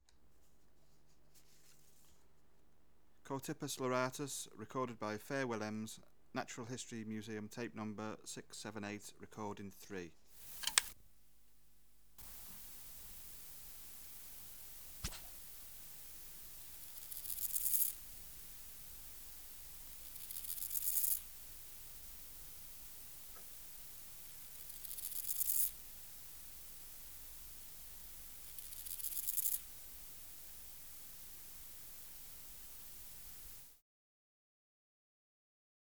Species: Chorthippus (Chorthippus) loratus
Recording Location: Room in private house. Eygelshoven, Netherlands.
Substrate/Cage: In cage
2 females in cage with male.
Microphone & Power Supply: AKG D202 E (LF circuit off) Distance from Subject (cm): 10
Recorder: Uher 4200